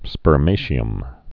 (spər-māshē-əm, -shəm)